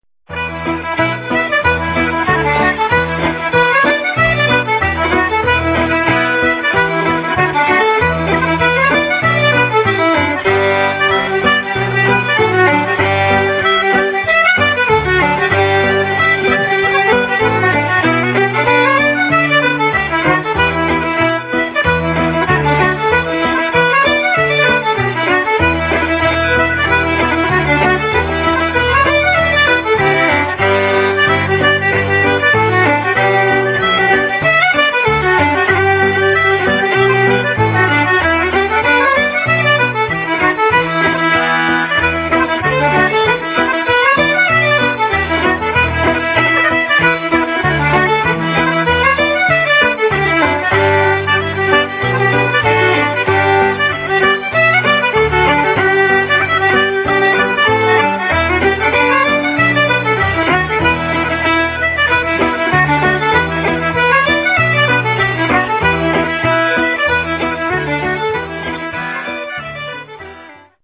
splendid four-part